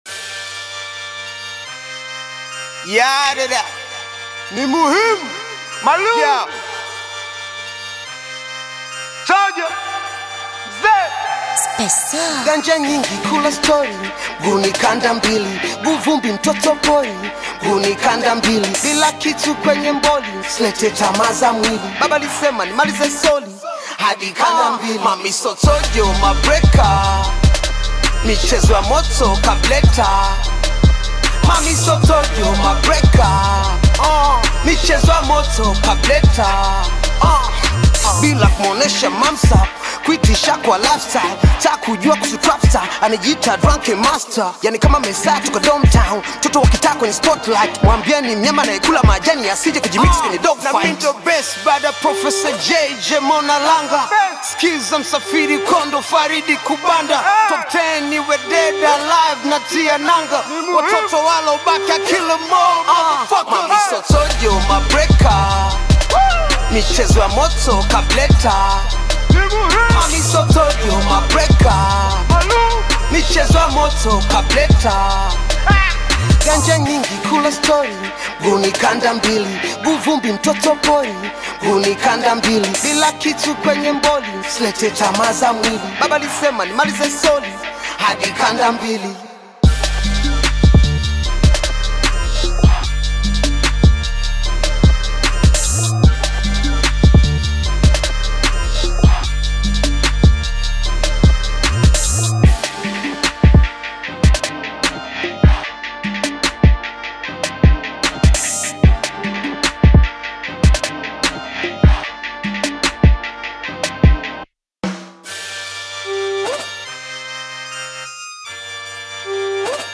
rap song